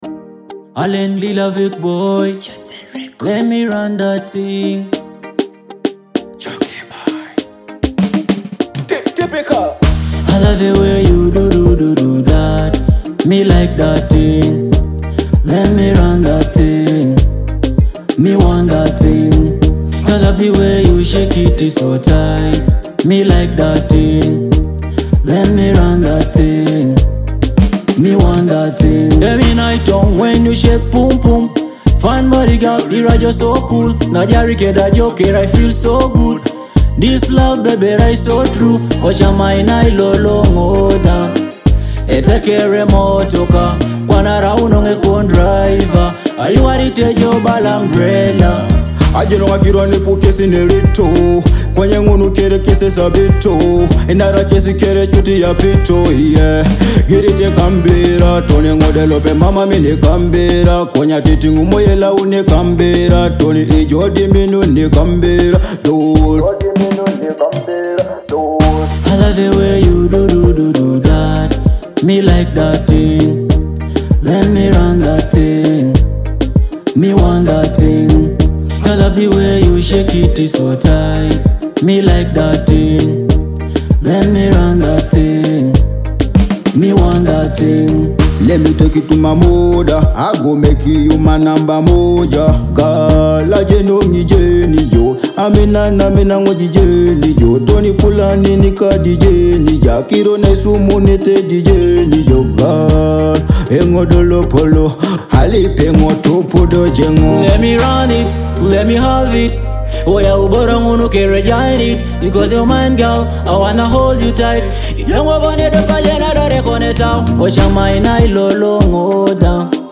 With its lively Teso-inspired rhythm and uplifting lyrics